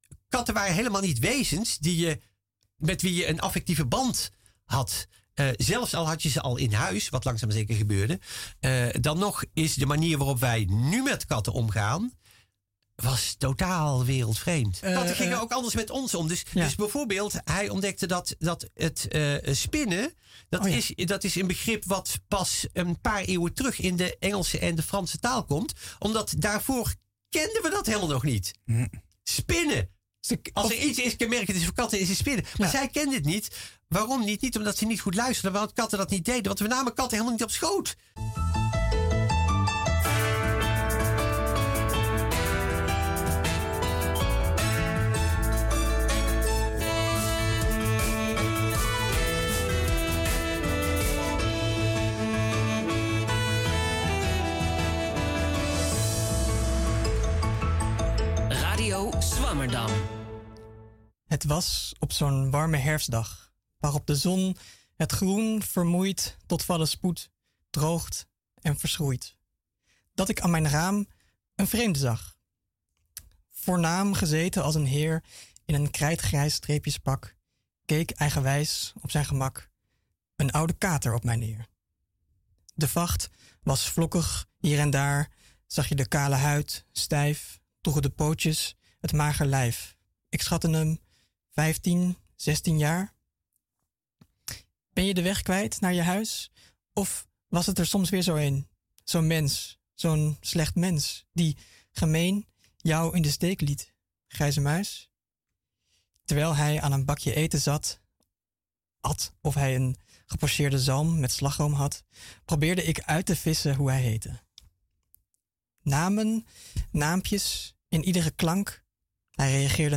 In onze knusse studio in Pakhuis de Zwijger schuiven wetenschappers aan om hun onderzoek uitgebreid en toegankelijk toe te lichten.